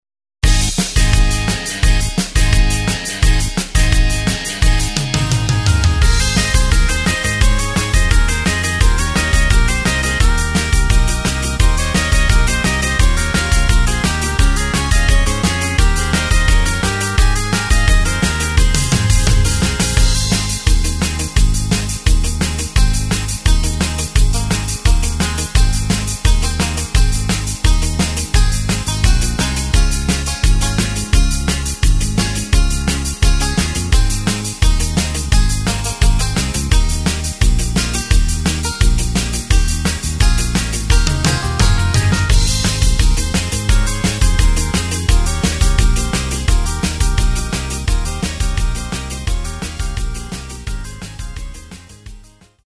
Tempo:         172.00
Tonart:            Ab
Country Song aus dem Jahr 1990!
Playback mp3 Demo